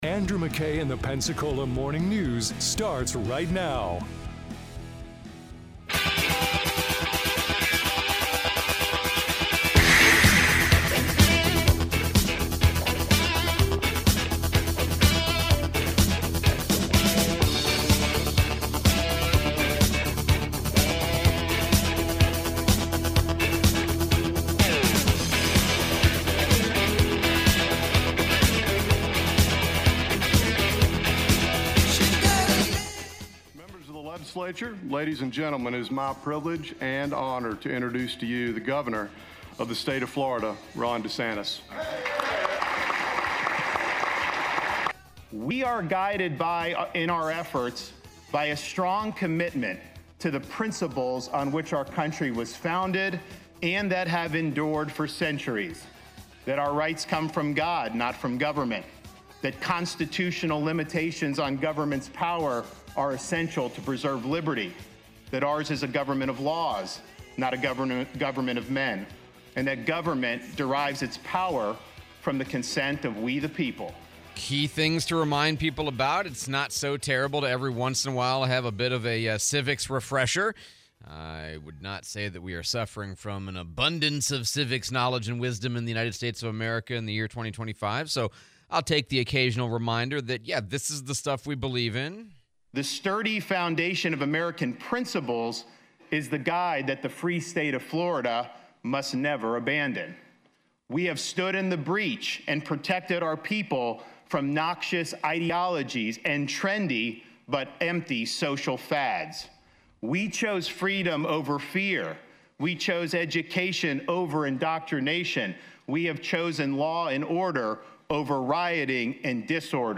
Gov. Desantis speech, replay Mayor DC Reeves